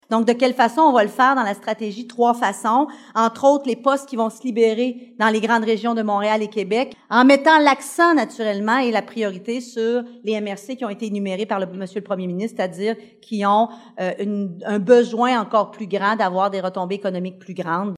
Le premier ministre du Québec, François Legault, a tenu une conférence de presse à l’Auberge du Draveur de Maniwaki, cet après-midi, afin d’annoncer le déploiement de son Plan de régionalisation de la fonction publique.
On écoute Sonia Lebel, ministre responsable de l’Administration gouvernementale :